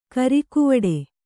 ♪ karikuvaḍe